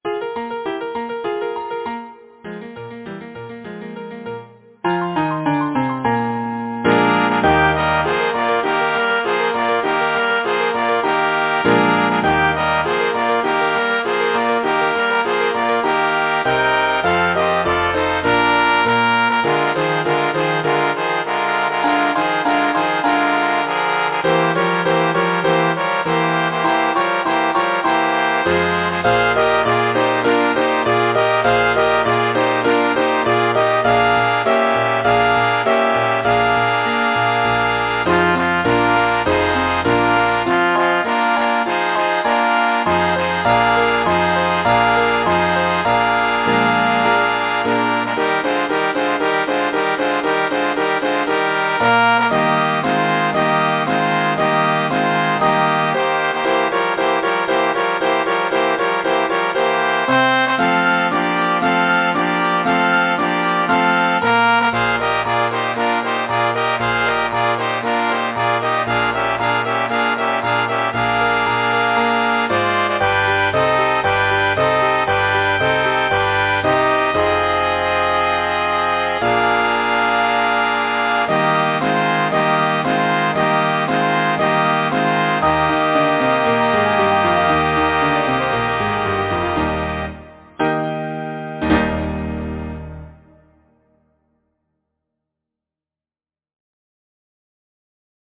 Title: Silver Sleigh-Bells Composer: Cuthbert Harris Lyricist: Edgar Allan Poe Number of voices: 4vv Voicing: SATB Genre: Secular, Partsong
Language: English Instruments: Piano